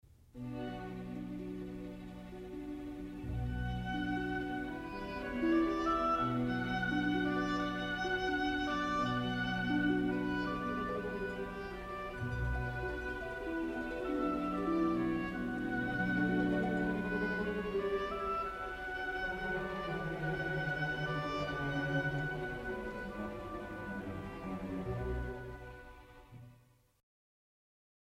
oboe
Ha una voce particolarmente espressiva, spesso utilizzata per proporre melodie intense ed appassionate.
oboe_solo_2.mp3